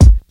Kicks
WU_BD_203.wav